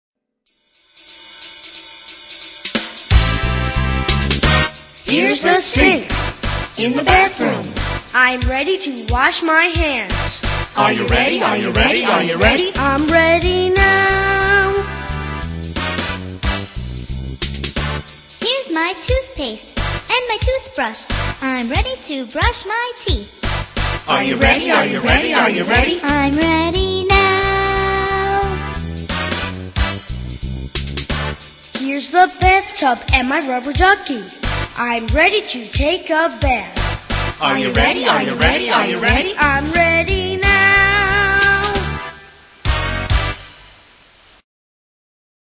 在线英语听力室英语儿歌274首 第63期:Here'the sink的听力文件下载,收录了274首发音地道纯正，音乐节奏活泼动人的英文儿歌，从小培养对英语的爱好，为以后萌娃学习更多的英语知识，打下坚实的基础。